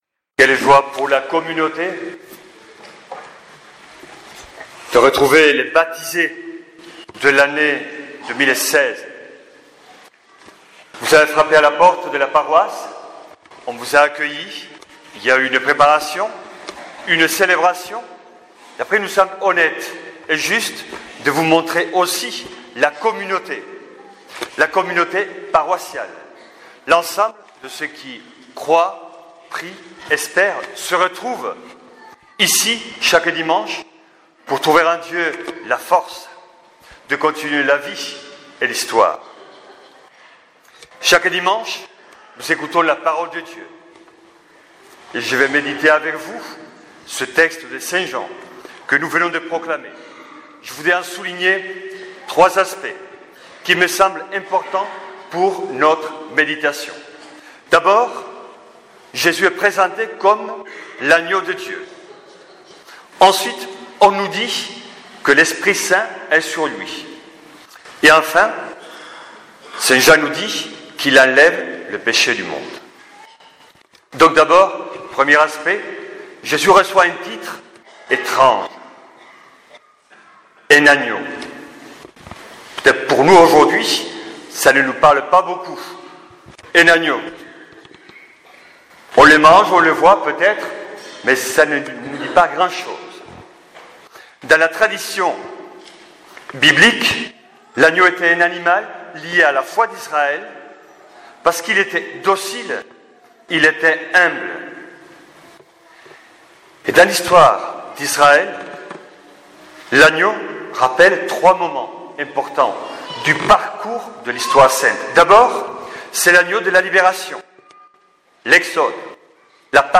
Fête des baptisés 2017
♦ Homélie :